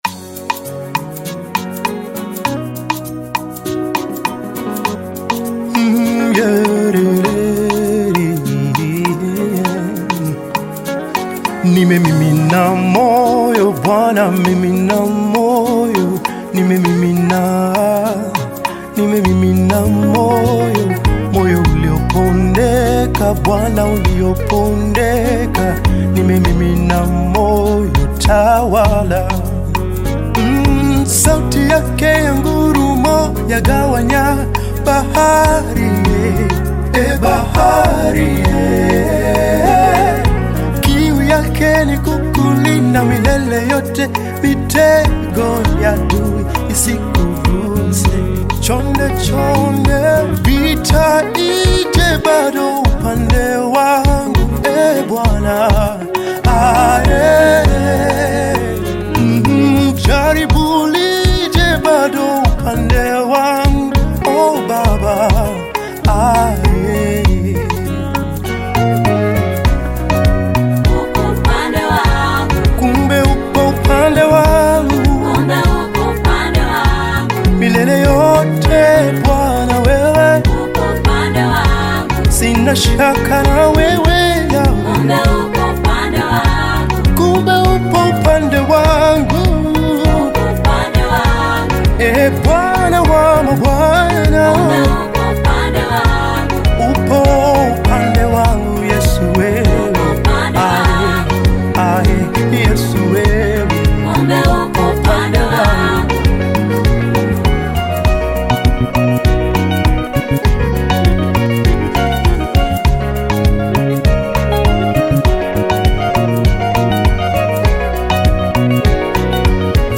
Tanzanian Gospel
soul-stirring vocals
create a spiritual atmosphere
African Music